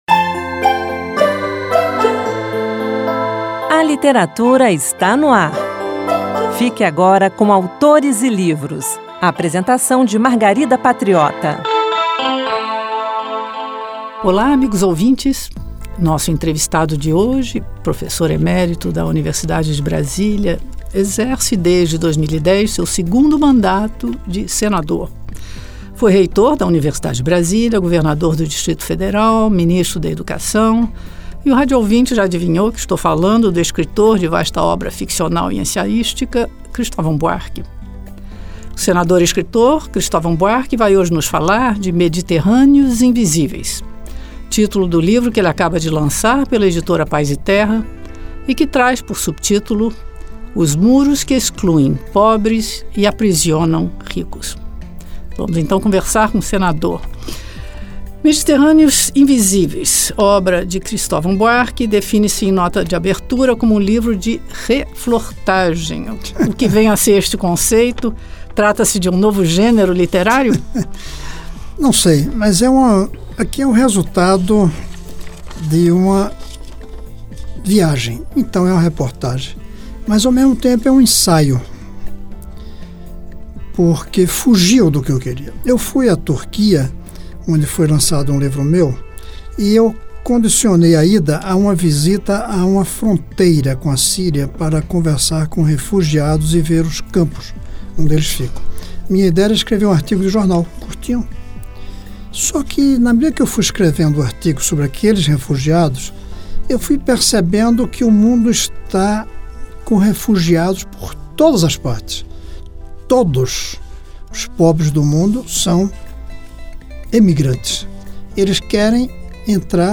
O programa Autores e Livros reprisa a entrevista com o professor emérito da Universidade de Brasília, que exerce, desde 2010, seu segundo mandato de senador, Cristovam Buarque.